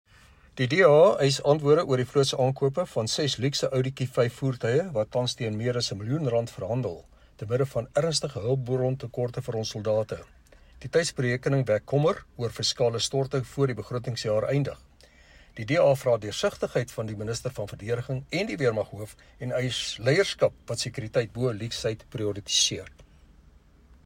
Please find attached soundbites in